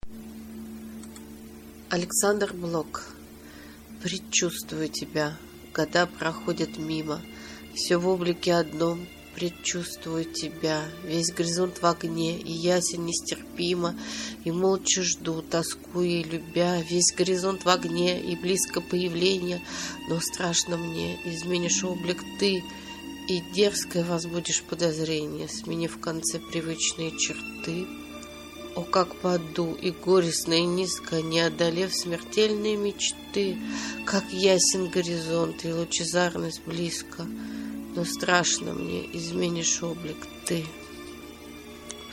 11. «Я читаю стихотворение А.Блока – Предчувствую Тебя. Года проходят мимо…» /